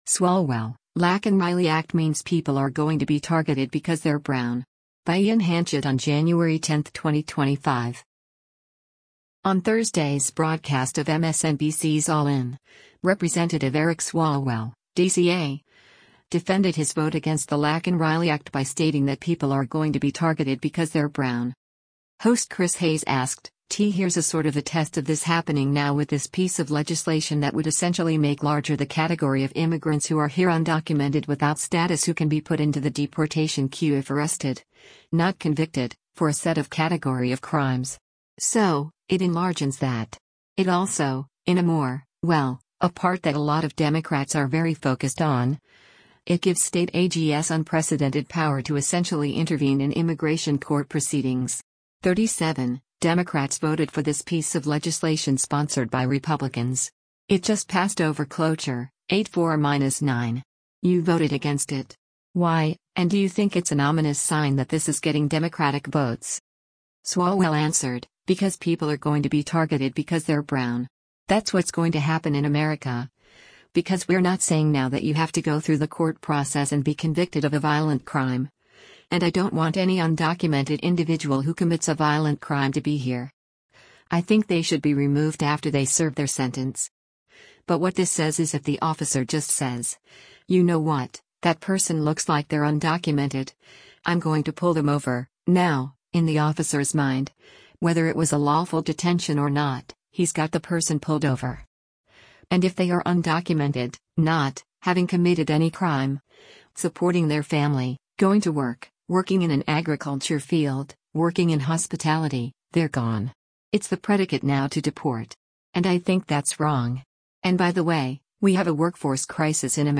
On Thursday’s broadcast of MSNBC’s “All In,” Rep. Eric Swalwell (D-CA) defended his vote against the Laken Riley Act by stating that “people are going to be targeted because they’re brown.”